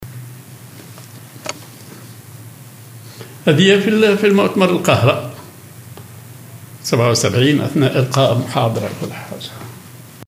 مؤتمر في القاهرة أثناء القاء محاضرة